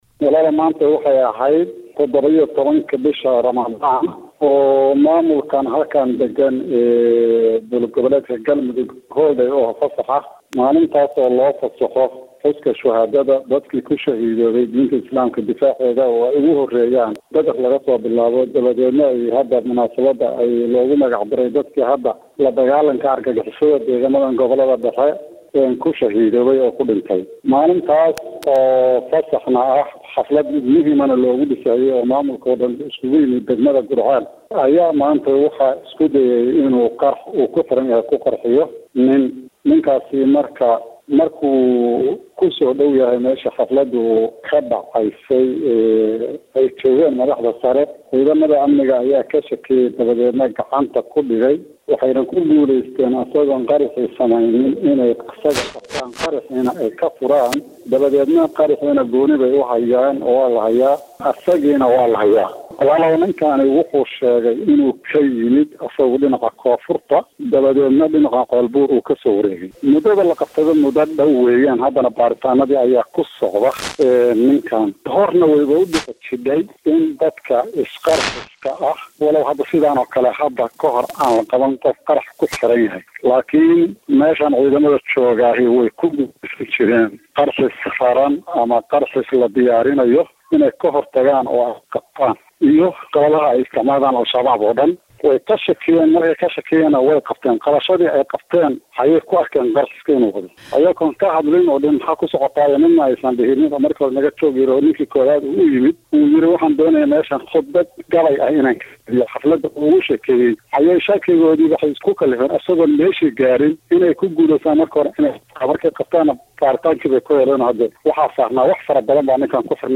Hoos ka dhageyso codka wasiirka
Saadaq-Sheekh-yuusuf-Ibarahim.mp3